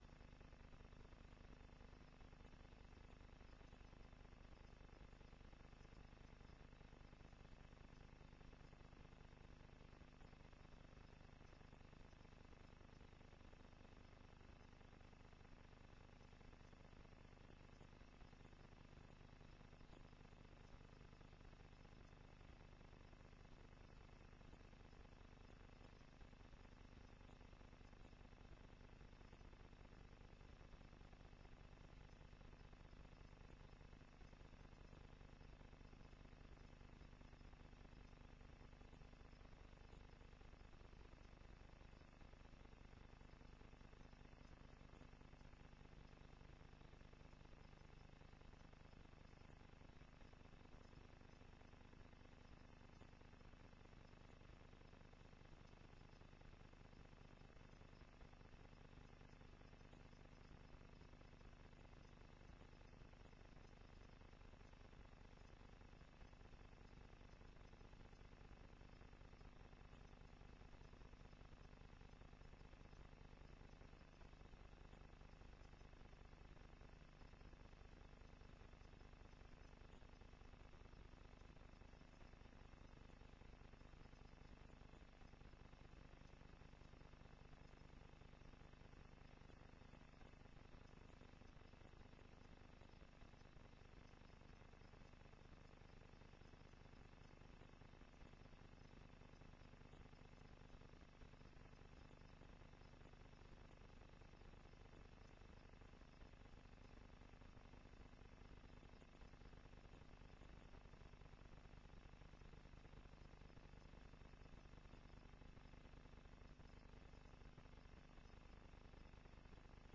Informatiebijeenkomst 30 november 2009 19:30:00, Gemeente Tynaarlo